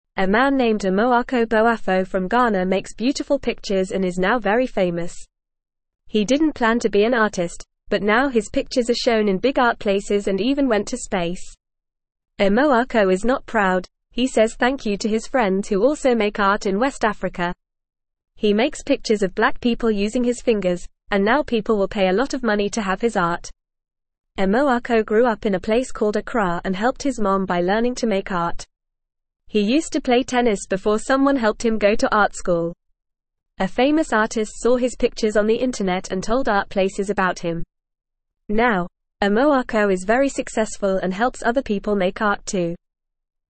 Fast
English-Newsroom-Beginner-FAST-Reading-Amoako-Boafo-A-Famous-Artist-from-Ghana.mp3